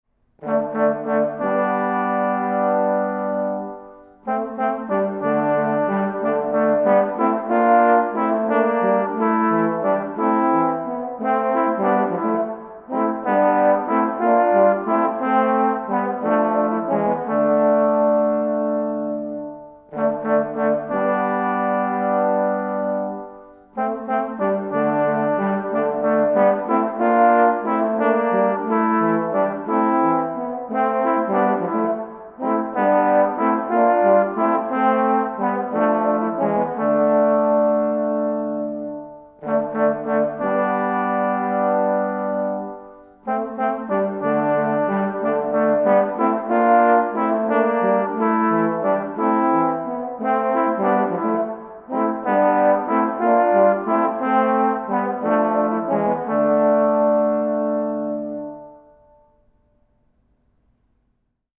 trio puzonowe